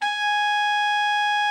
TENOR 37.wav